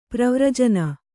♪ pravrajana